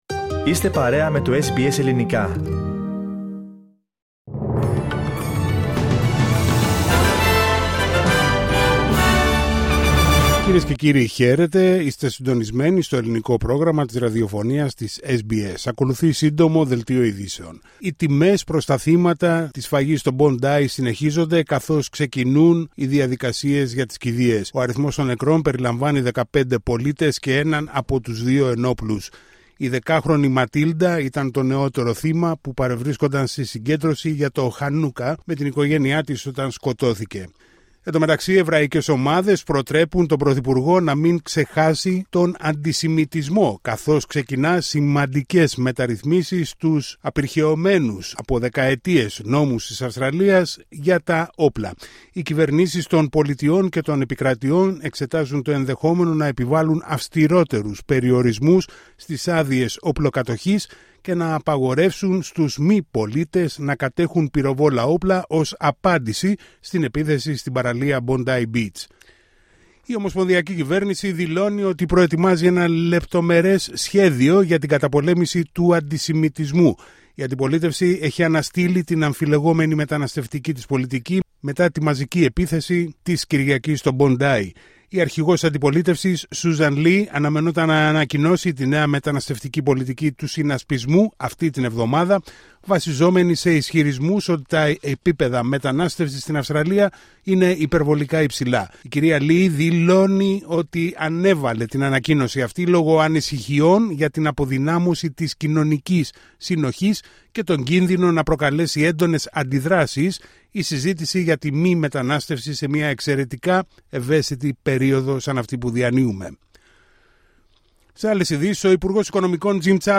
Σύντομο δελτίο ειδήσεων στα Ελληνικά από την Αυστραλία την Ελλάδα την Κύπρο και όλο τον κόσμο